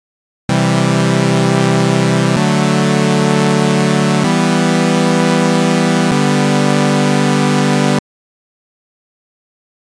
コードMIDI (Key=GM, BPM=128)
イニシャライズの音はこのようになっています。